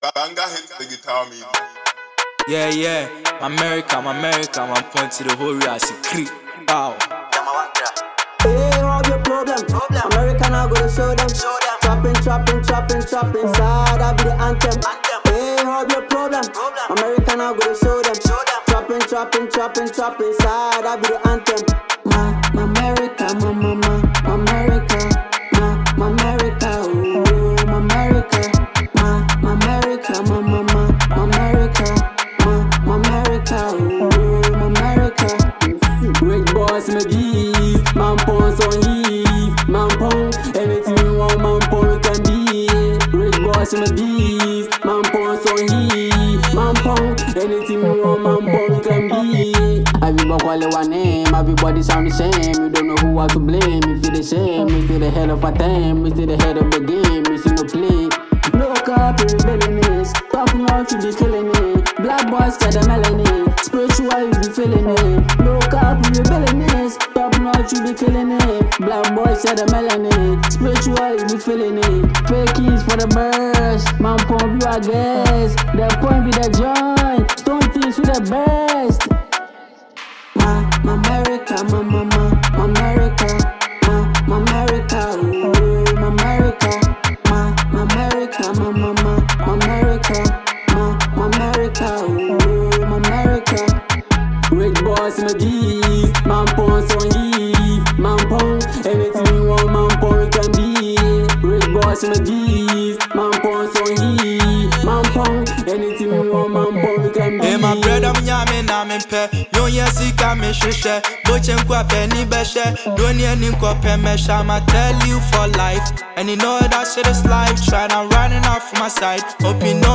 This track is pure, unadulterated energy.